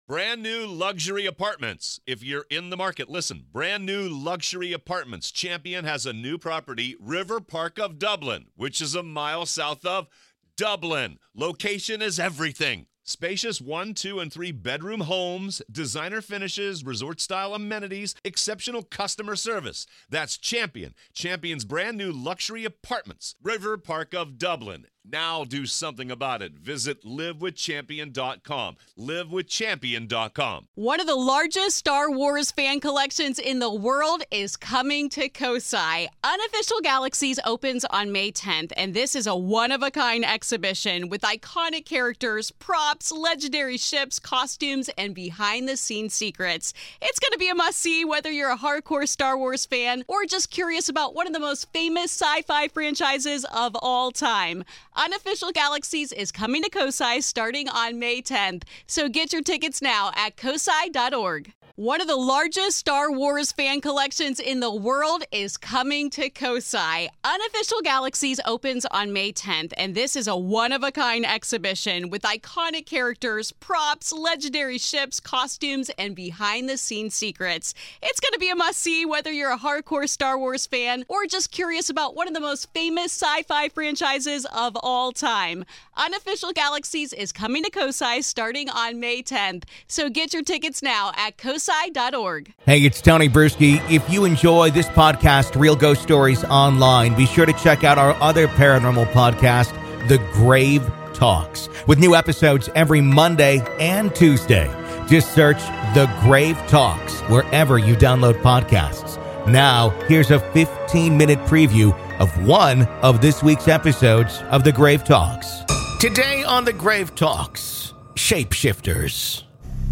PART 2 - AVAILABLE TO GRAVE KEEPERS ONLY - LISTEN HERE In part two of our interview, available only to Grave Keepers , we discuss: What are Skinwalkers and what can they do?